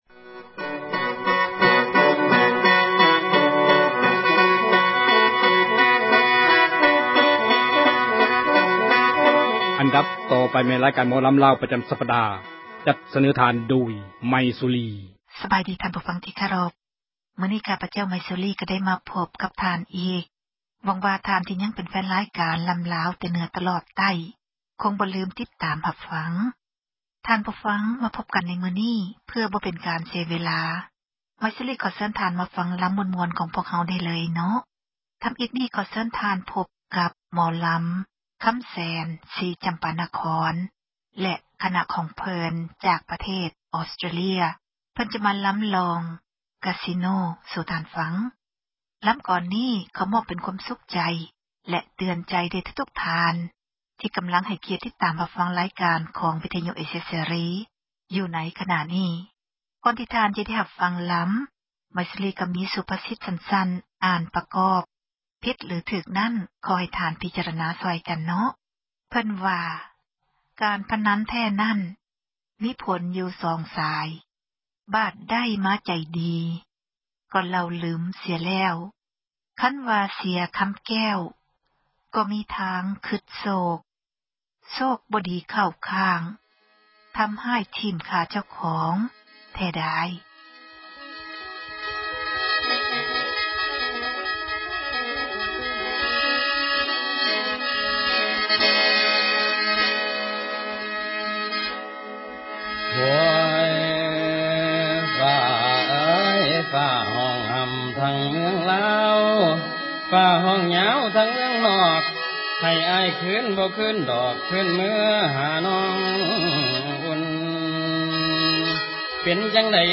ຣາຍການ ໝໍລຳ ລາວ ປະຈຳ ສັປດາ.